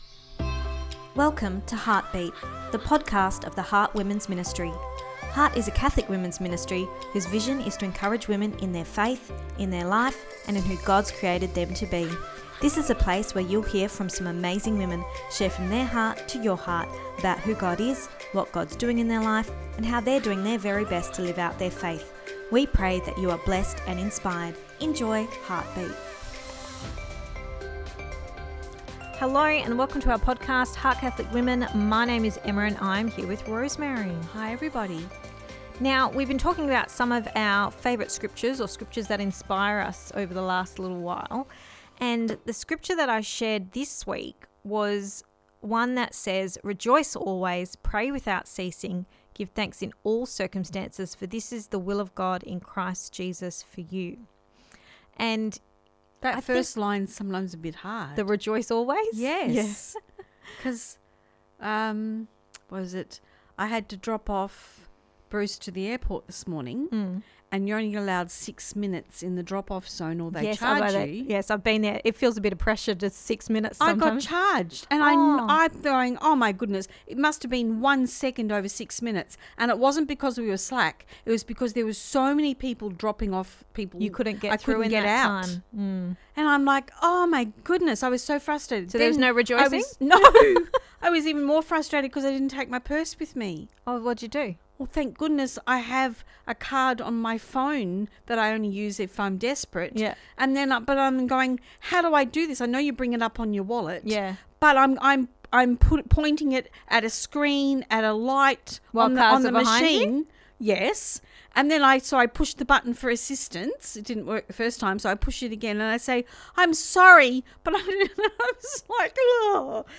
Ep271 Pt2 (Our Chat) – How to Live during Hardship